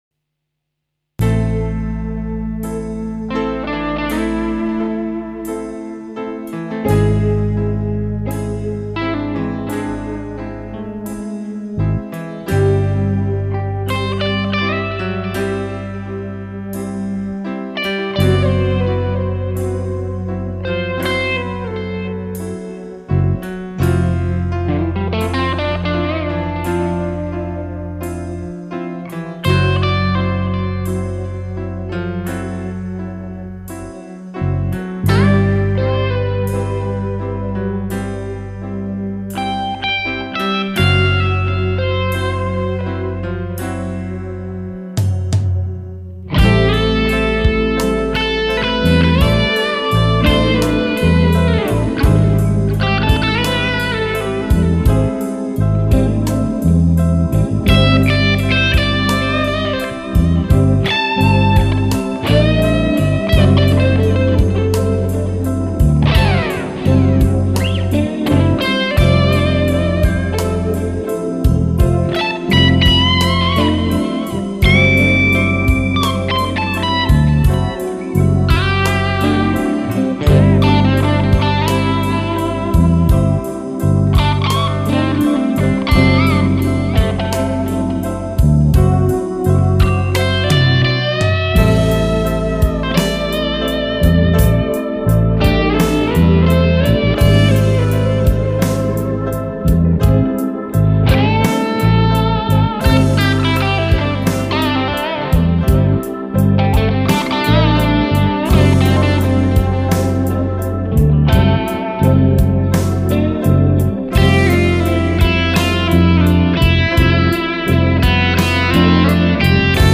Ihr hört meine Tokai Junior mit dem neuen Zhangliqun P90 und dem Womanizer.